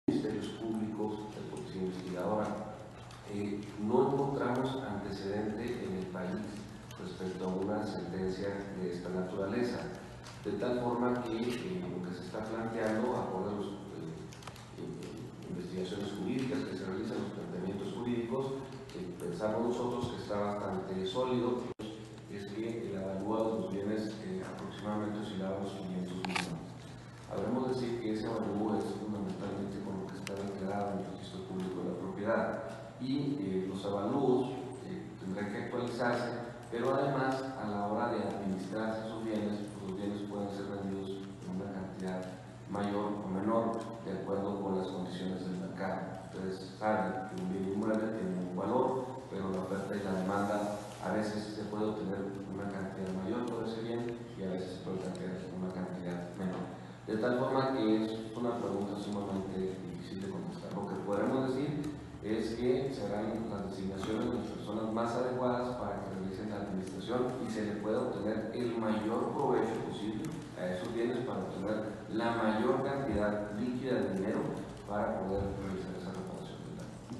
AUDIO: HELIDORO ARAIZA, FISCAL DE DISTRITO ZONA CENTRO (FGE)